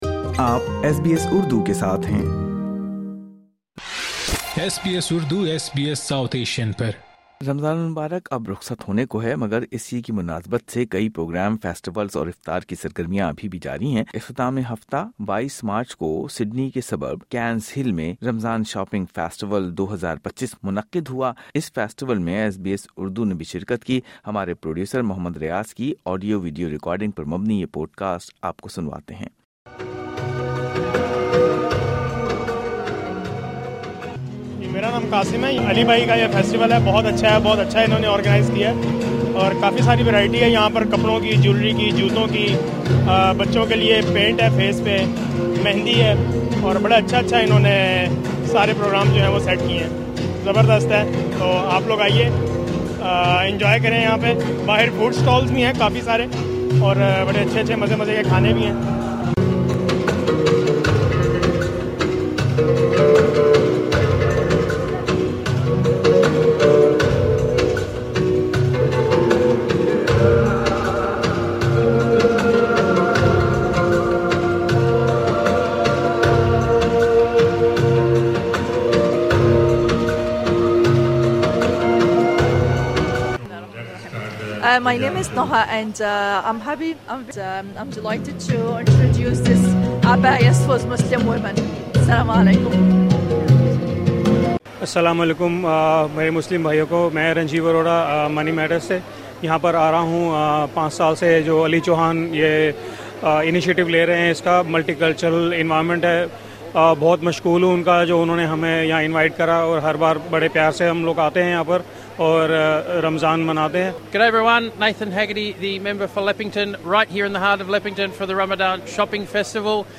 رمضان شاپنگ فیسٹیول 2025 ایک شاندار اور بھرپور ثقافتی میلہ جو روایتی پکوانوں اور دیدہ زیب ملبوسات کی دلکشی سے مزین تھا۔اس رنگا رنگ رمضان میلے کی صوتی رپورٹ میں اس سال کے میلے کا احوال سنئے۔